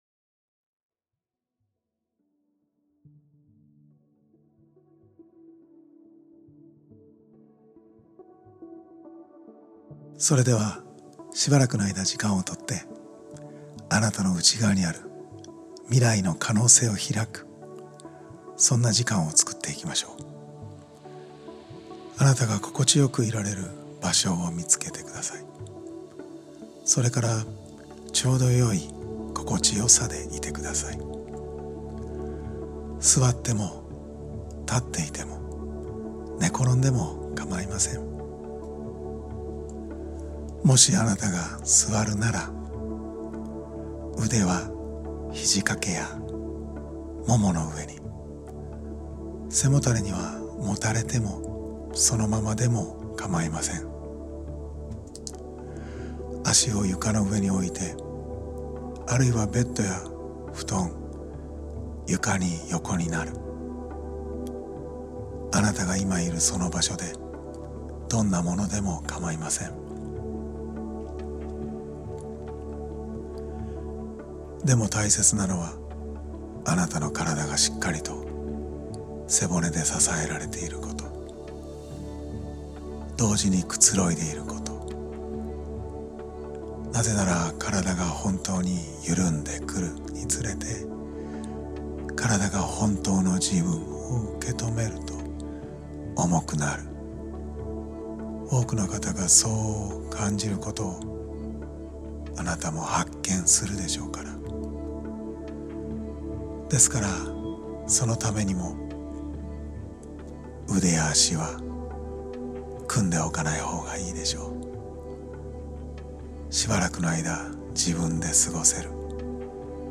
【催眠音声】あなたの内側にある未来への可能性を開く トランス（催眠）音声 | 一般社団法人変化と成長のコミュニケーション推進協会